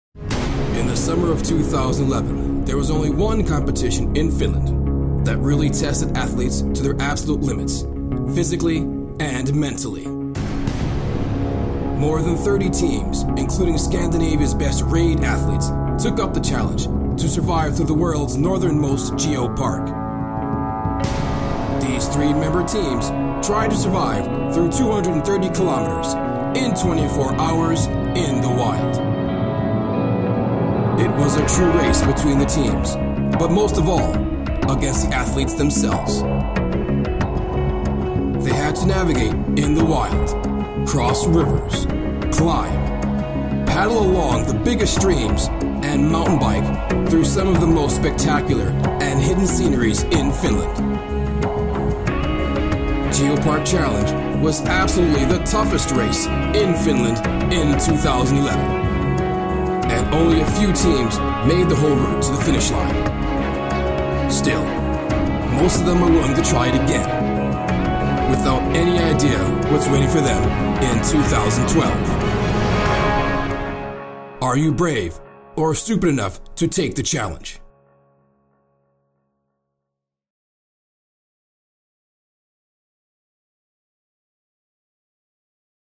影视片花-霸气侧漏